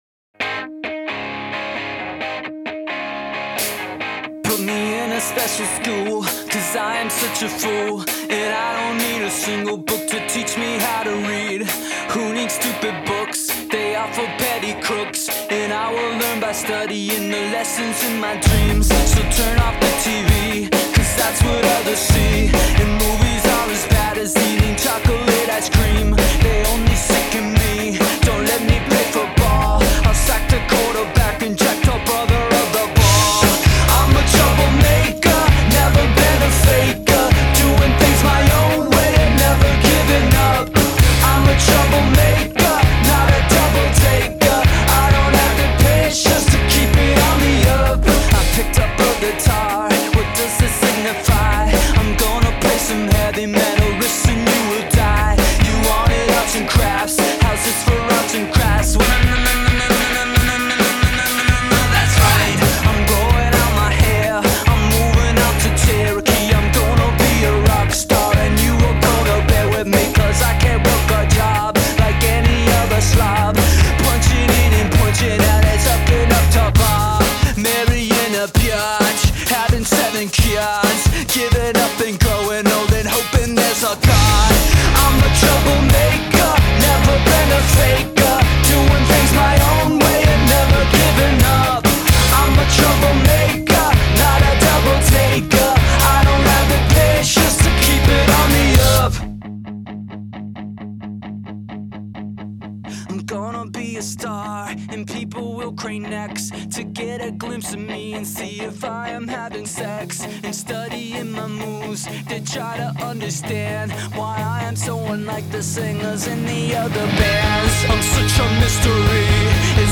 Click track or unassisted drummer?